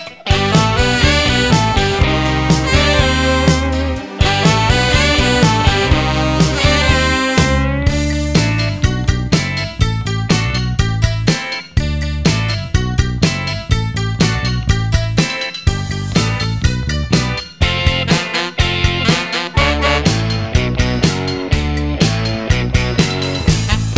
no Backing Vocals Ska 3:17 Buy £1.50